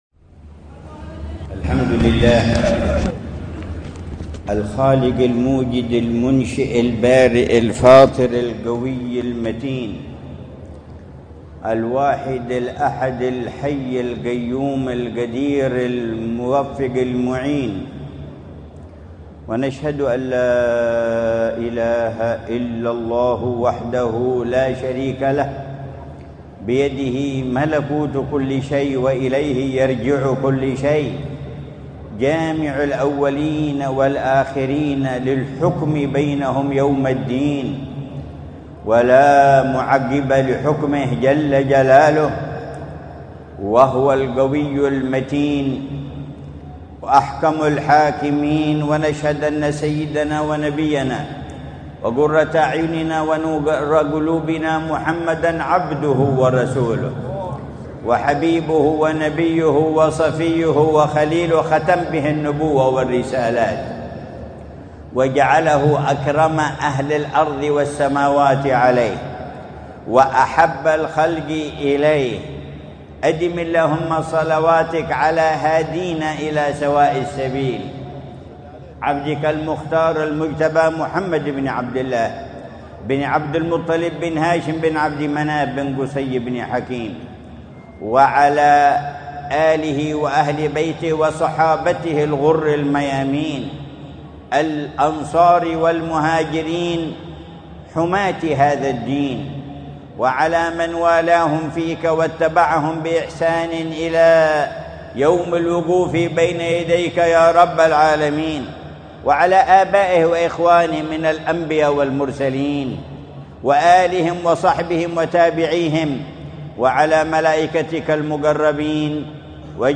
محاضرة العلامة الحبيب عمر بن محمد بن حفيظ في مجلس الذكر والتذكير بمناسبة افتتاح جامع الحامد في منطقة دمون، بمدينة تريم، حضرموت، ليلة الأربعاء 25 جمادى الأولى 1446هـ بعنوان: